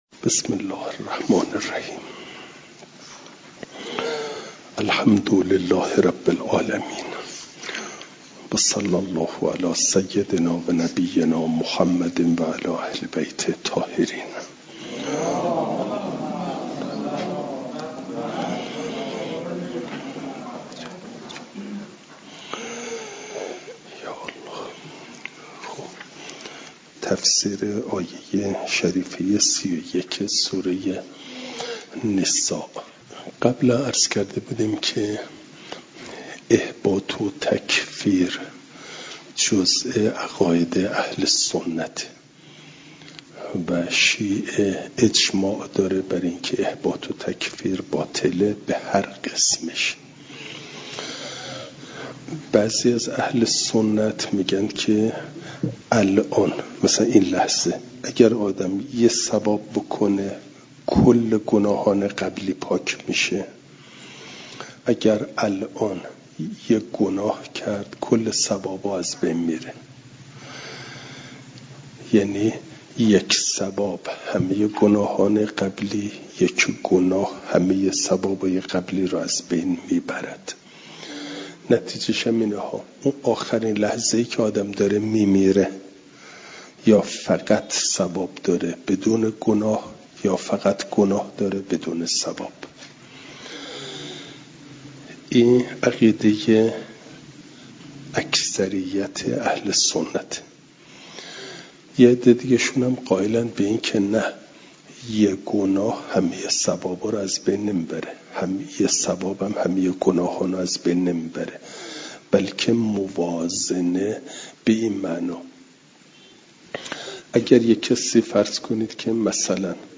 جلسه سیصد و پنجاه و هفتم درس تفسیر مجمع البیان